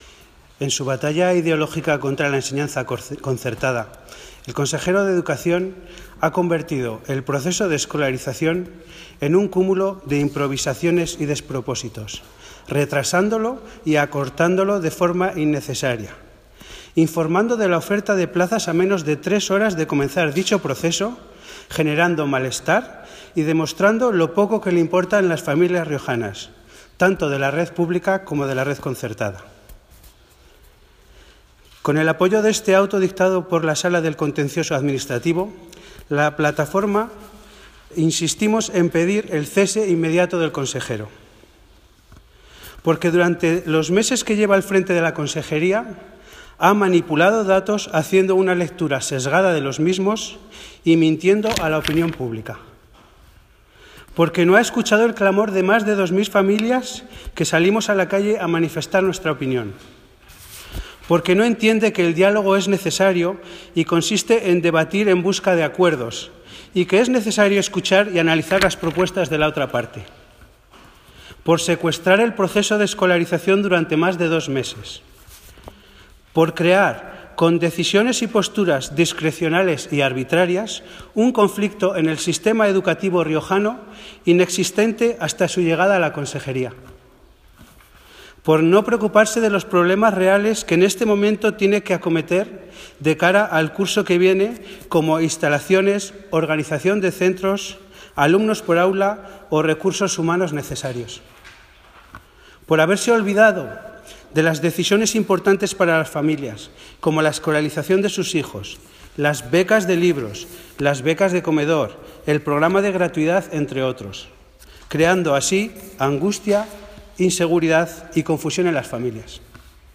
Audios con las intevenciones en la rueda de prensa y por orden de participación: